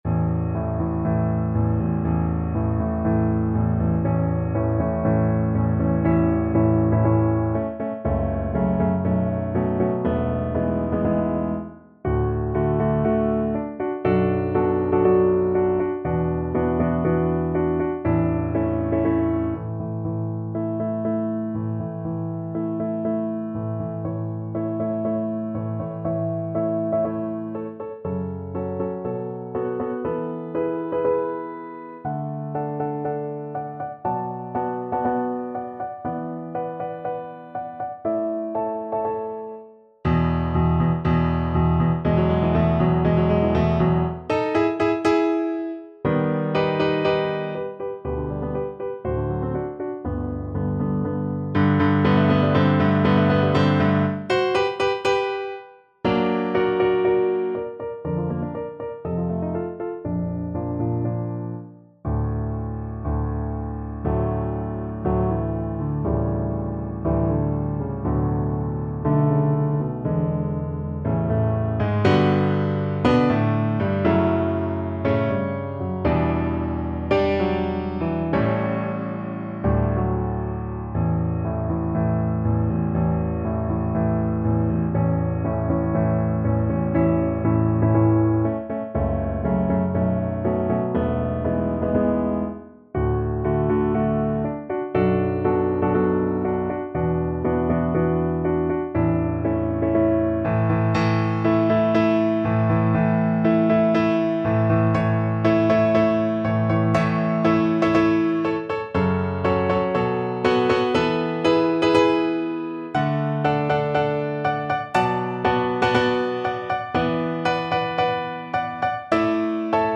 Play (or use space bar on your keyboard) Pause Music Playalong - Piano Accompaniment Playalong Band Accompaniment not yet available reset tempo print settings full screen
~ = 120 Tempo di Marcia un poco vivace
A major (Sounding Pitch) (View more A major Music for Cello )
Classical (View more Classical Cello Music)